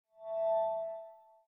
Folder_Close.ogg